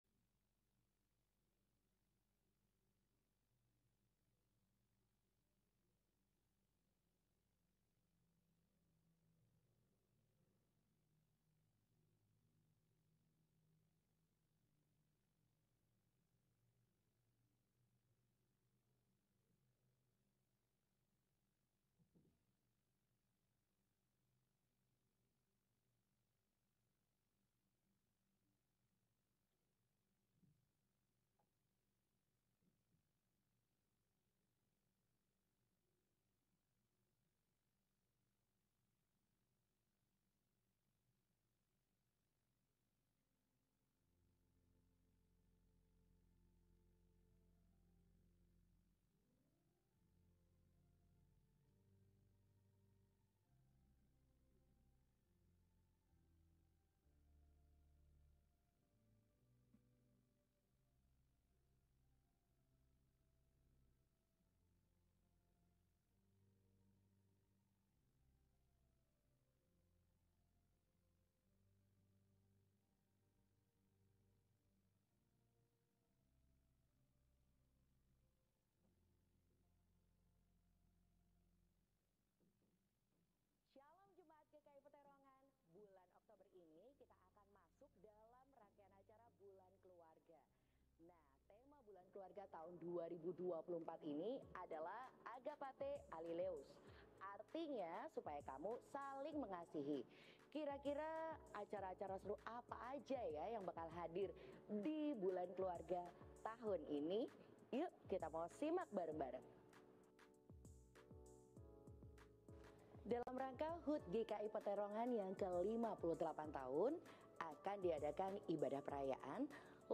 Panggillah Namaku – Ibadah Minggu (Pagi 1)